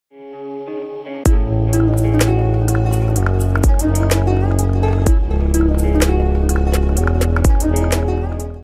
herkesin-aradigi-romantik-ve-huzunlu-rusca-sarki-sizin-icin-buldum-layk-atin.mp3